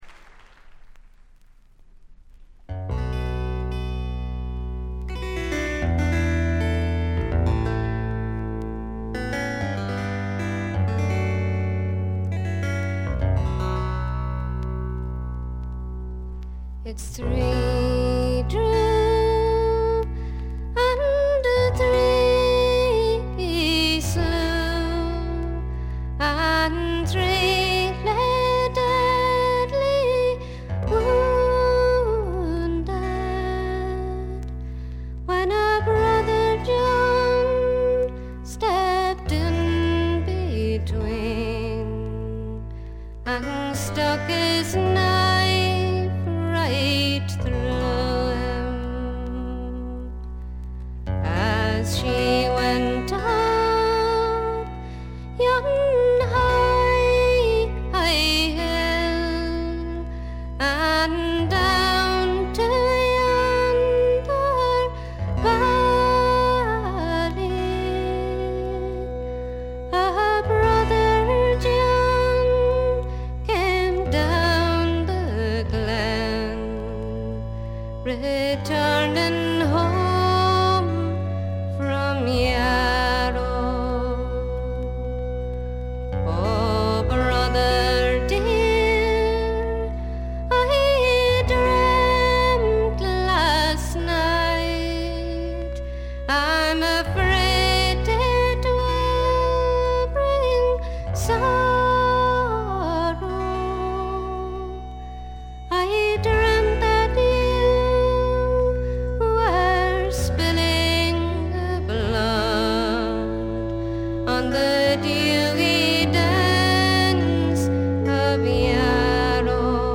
わずかなノイズ感のみ。
試聴曲は現品からの取り込み音源です。